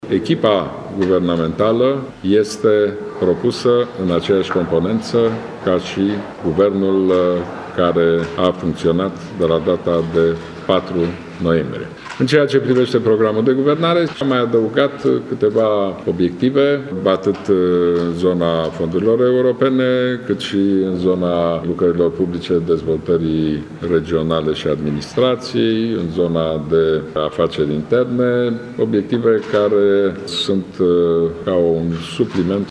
Premierul desemnat Ludovic Orban a transmis într-o declarație de presă că va merge la votul de învestire în Parlament cu aceeași formulă pentru Guvern.